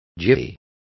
Complete with pronunciation of the translation of jiffy.